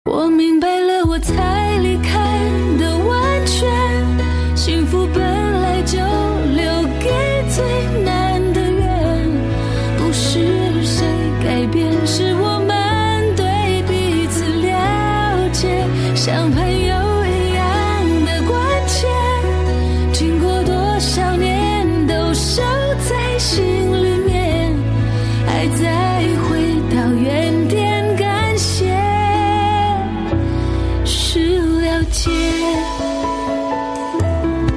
M4R铃声, MP3铃声, 华语歌曲 106 首发日期：2018-05-16 00:10 星期三